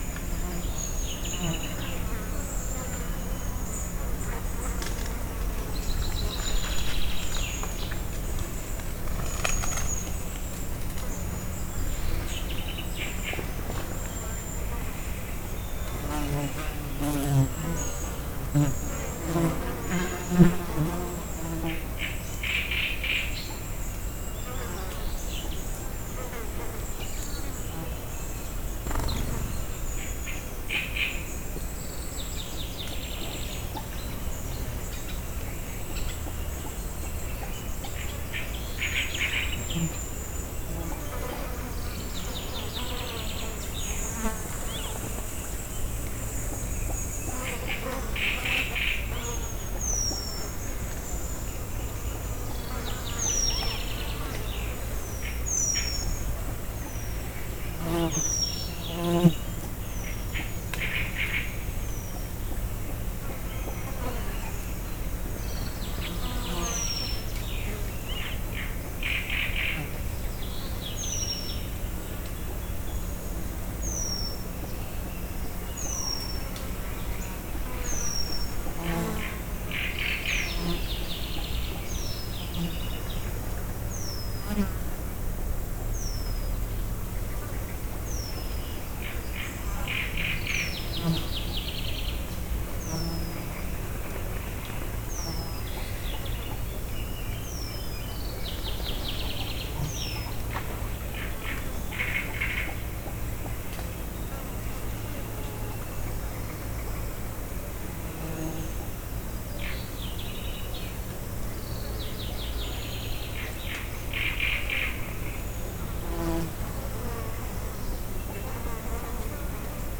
Directory Listing of /_MP3/allathangok/termeszetben/rovarok_premium/
eldorado_tiszababolna02.16.wav